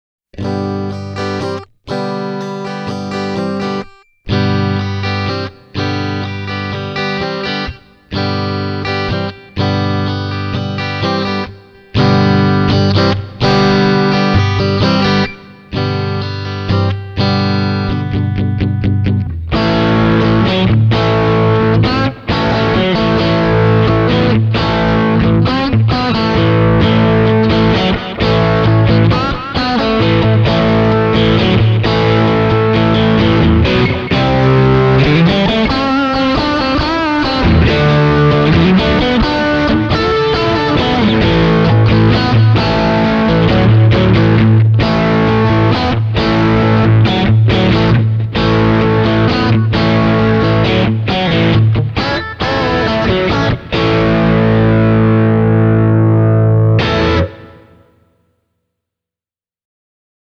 Here’s a run-through of all of the Fly Rig 5’s effects, played on a maple-necked Fender Stratocaster. The clip starts with the Fly Rig turned off: